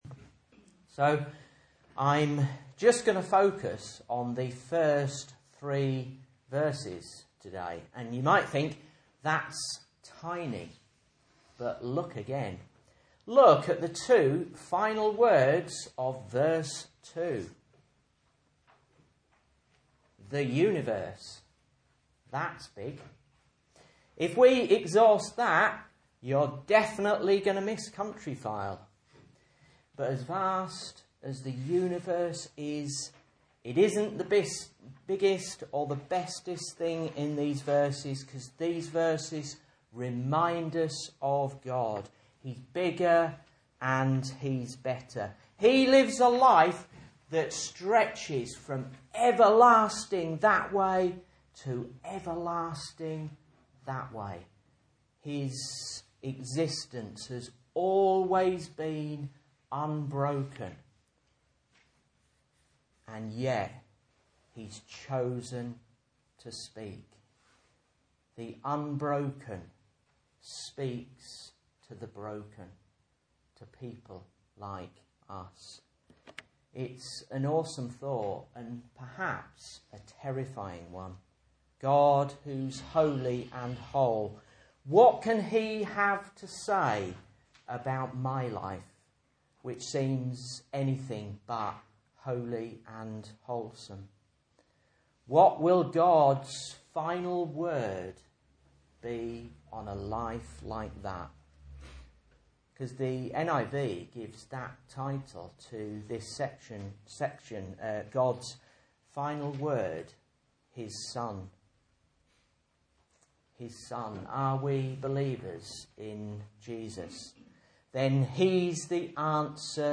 Message Scripture: Hebrews 1:1-3 | Listen